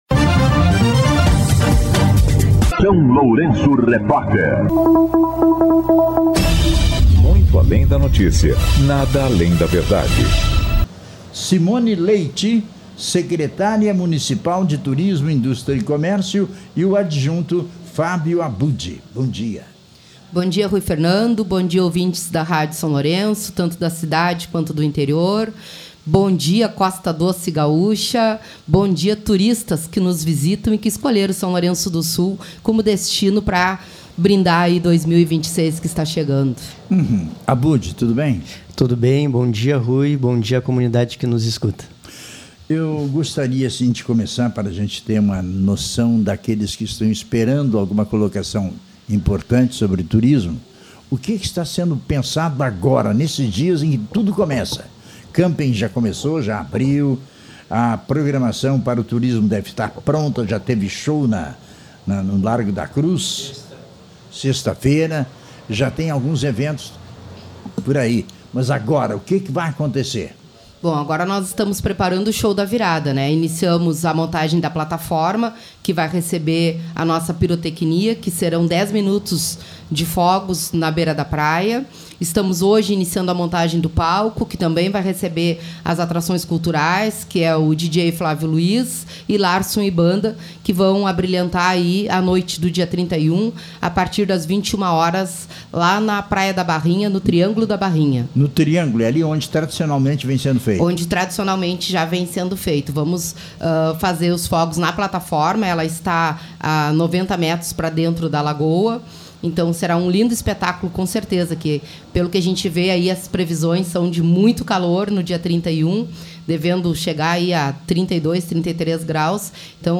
A secretária de Turismo, Simone Leite, e o secretário-adjunto, Fábio Abbud, estiveram no SLR RÁDIO nesta segunda-feira (29) para detalhar o Show da Virada em São Lourenço do Sul, que acontecerá no Triângulo da Praia da Barrinha.